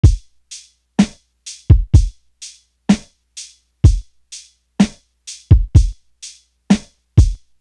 NB Drum.wav